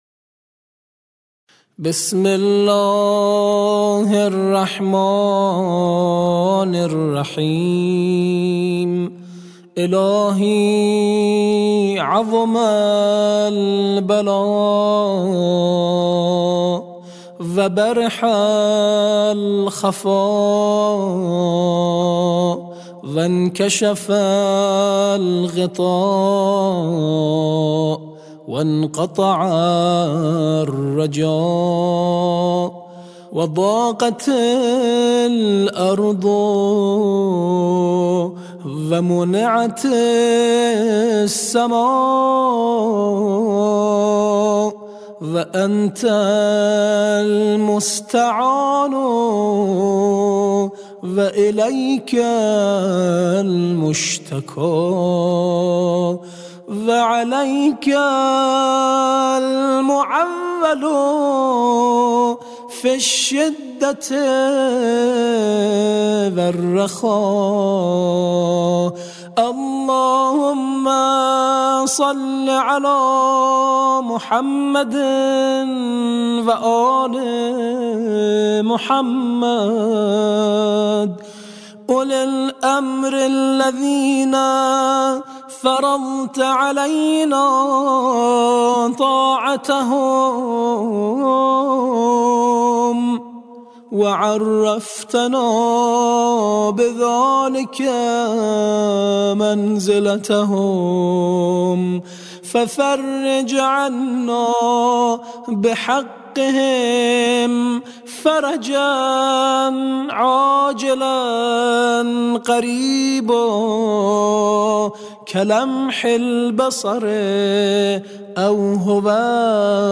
همچنین دعای فرج با صدای این قاری و مدرس قرآن را در ادامه خواهید شنید.